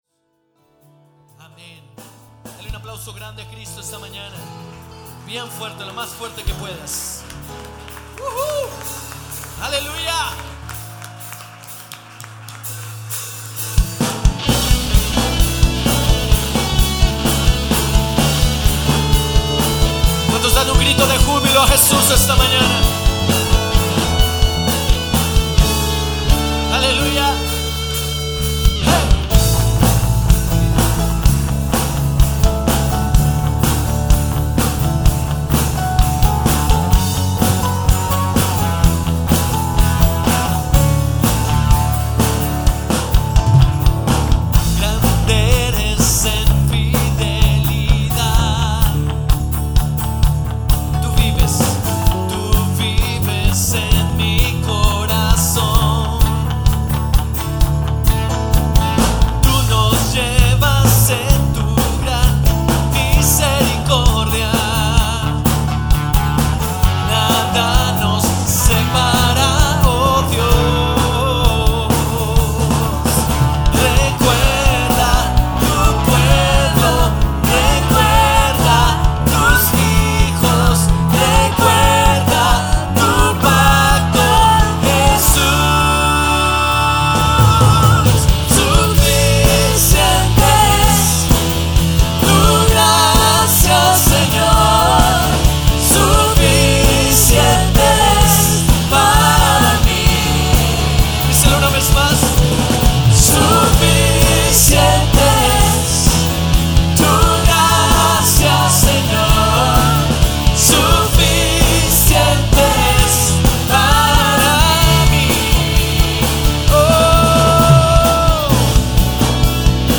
alabanza abril 3, 2016. Segunda reunion.mp3